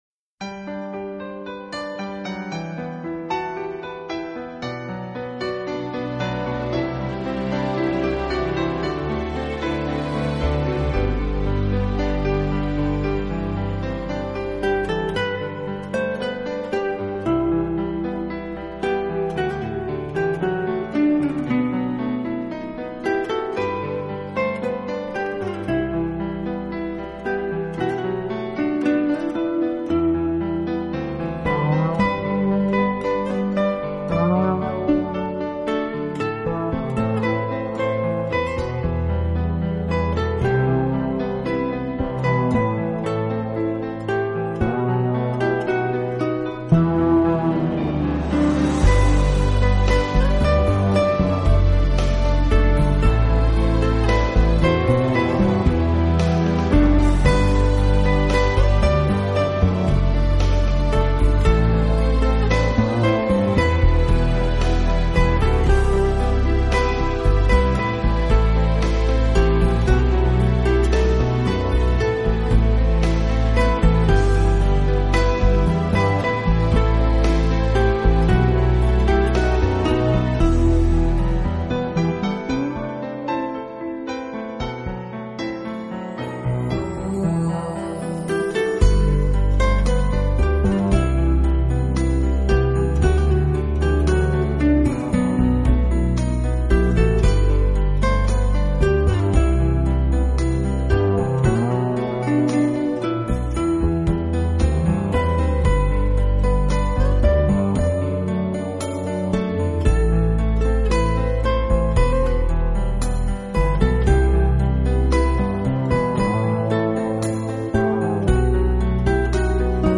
【吉他名辑】
由钢琴和吉他共同演绎的